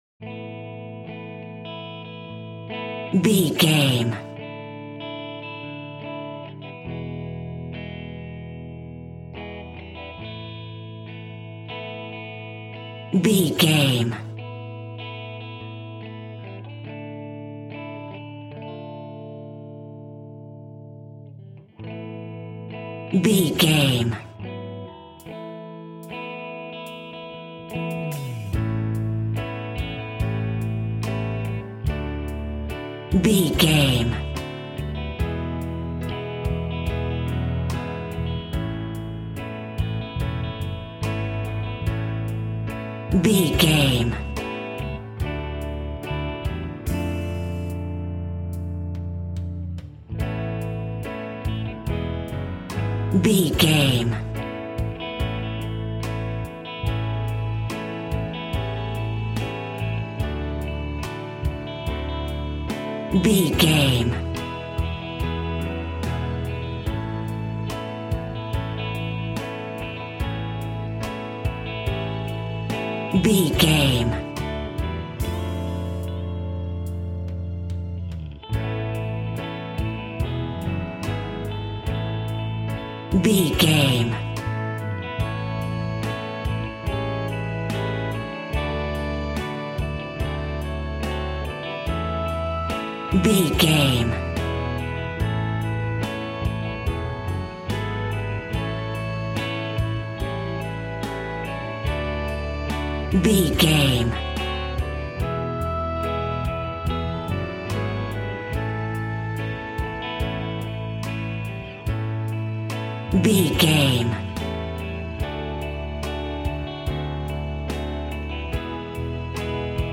Ionian/Major
pop
cheesy
pop rock
synth pop
drums
bass guitar
electric guitar
piano
hammond organ